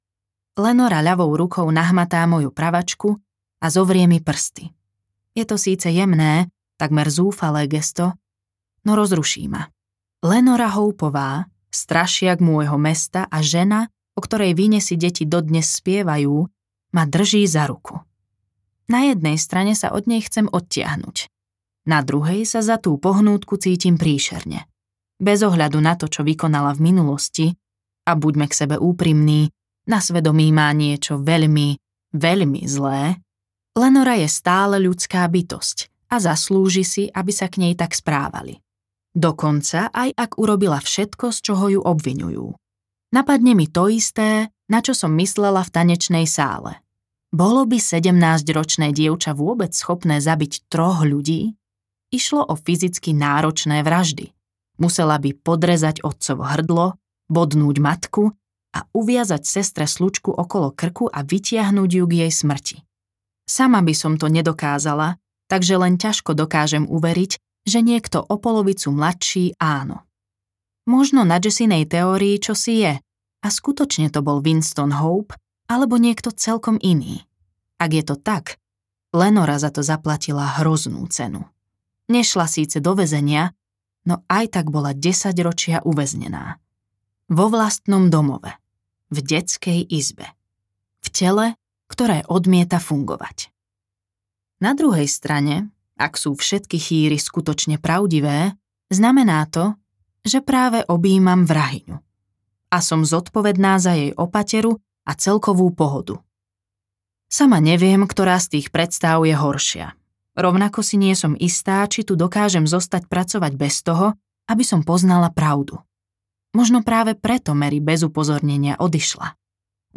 Prežila len jediná audiokniha
Ukázka z knihy